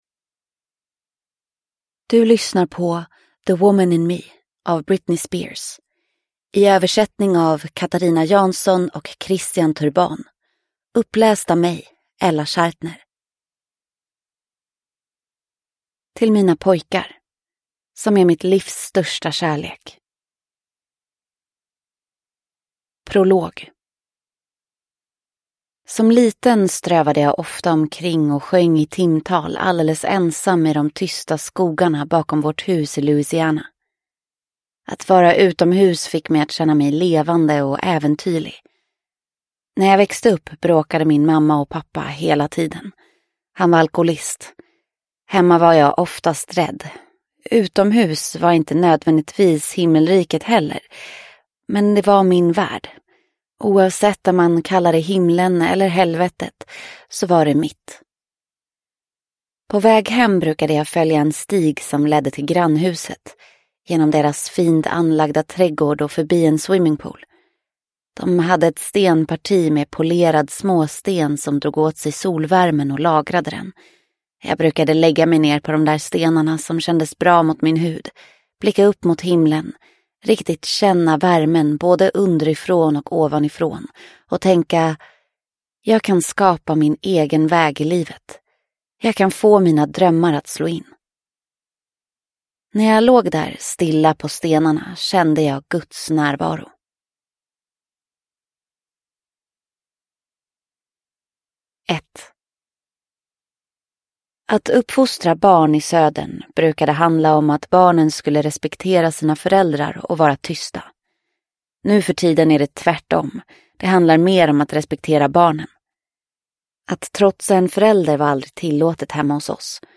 The woman in me (svensk utgåva) – Ljudbok – Laddas ner